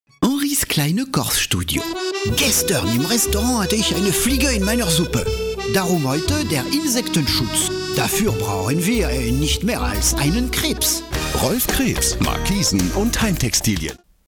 Sprecher französisch.
Kein Dialekt
Sprechprobe: Sonstiges (Muttersprache):
french voice over artist